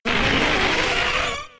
Cri de Paras K.O. dans Pokémon X et Y.